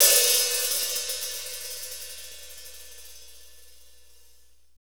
Index of /90_sSampleCDs/Northstar - Drumscapes Roland/DRM_R&B Groove/HAT_R&B Hats x
HAT R B OH09.wav